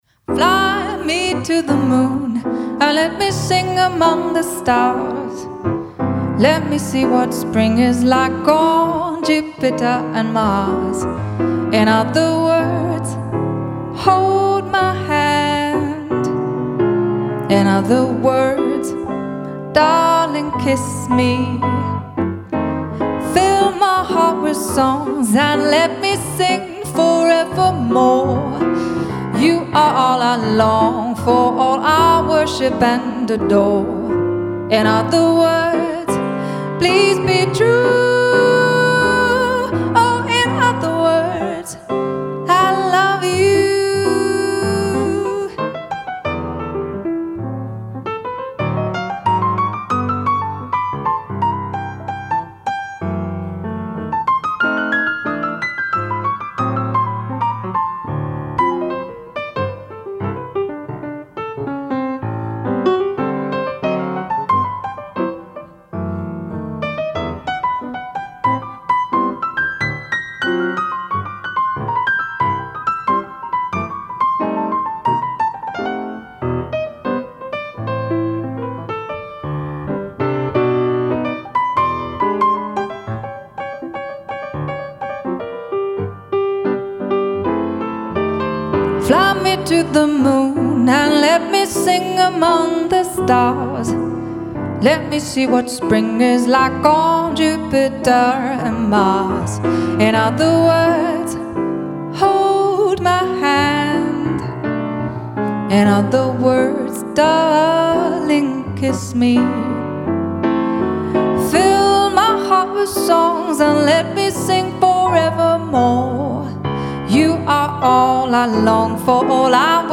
Livemusik als Highlight eurer Hochzeit
• Breit gefächertes Repertoire, Jazz & Pop